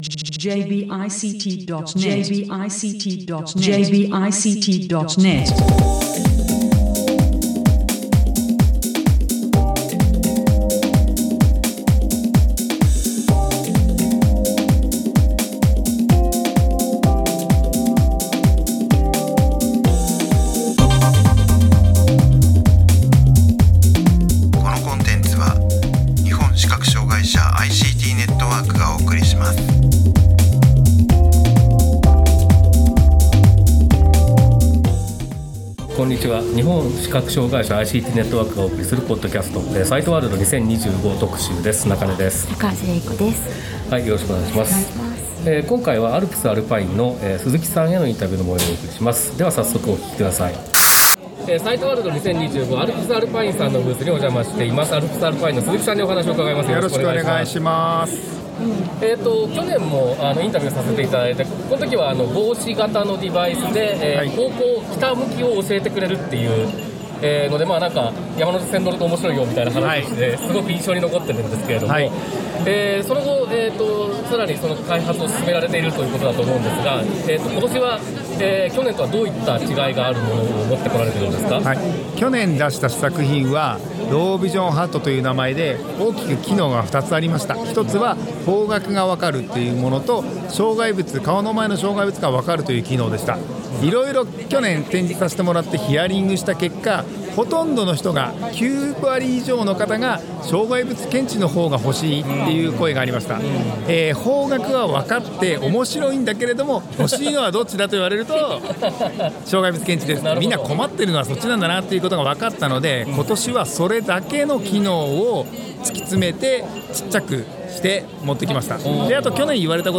TJS LA Morning 8 to 9 am Monday Live Show